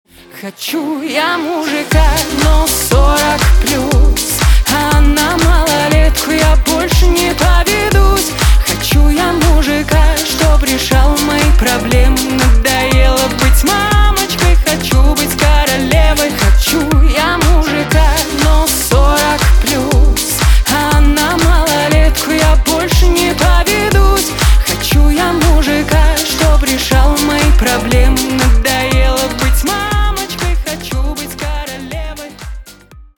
• Качество: 320, Stereo
веселые
женские